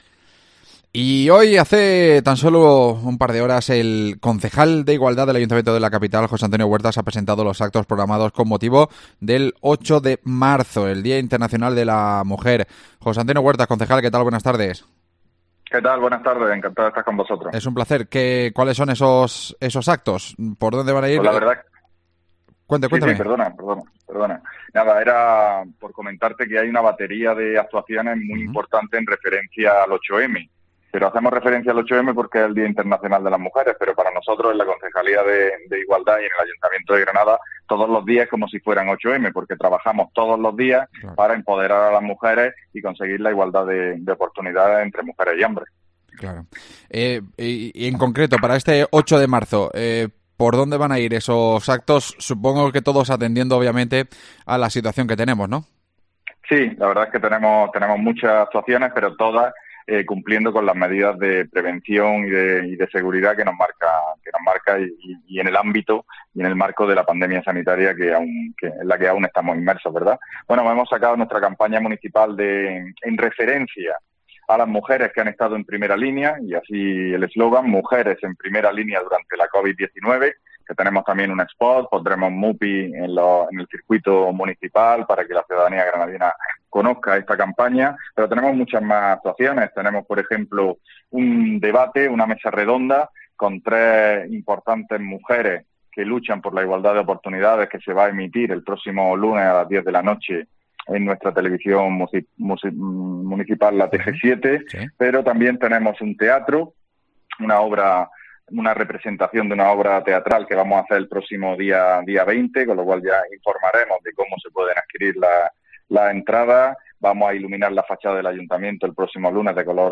El concejal de igualdad ha informado, en COPE, de este recorte tan importante para la lucha contra esta lacra